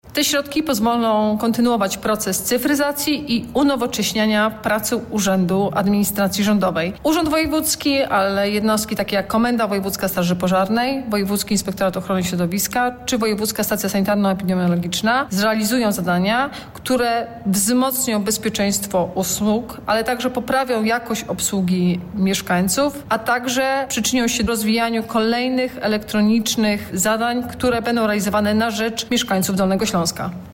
Wsparcie otrzymają takie jednostki, jak: Komenda Wojewódzka Państwowej Straży Pożarnej, Wojewódzka Stacja Sanitarno-Epidemiologiczna, Wojewódzki Inspektorat Inspekcji Handlowej, Wojewódzki Inspektorat Ochrony Środowiska, Wojewódzki Urząd Ochrony Zabytków, mówi Anna Żabska, Wojewoda Dolnośląska.